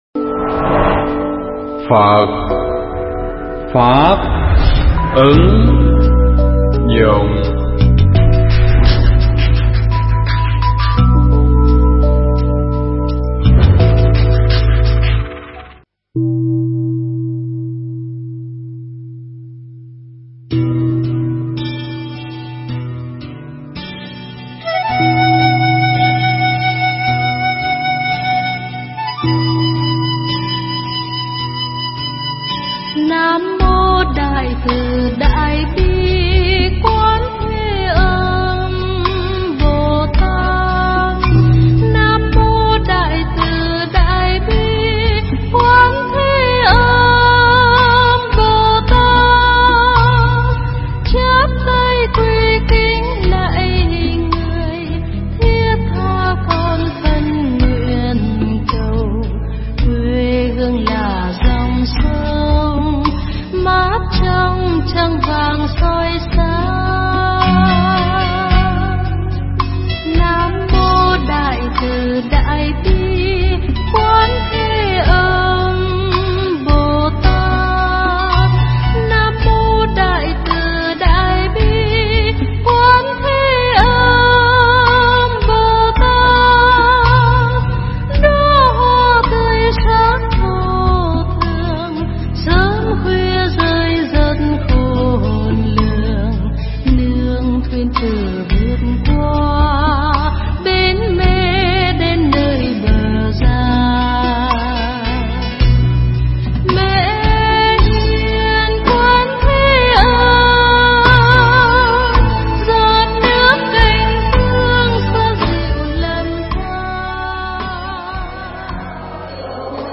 Nghe Mp3 thuyết pháp Bổn Môn Pháp Hoa Và Ý Nghĩa Pháp Y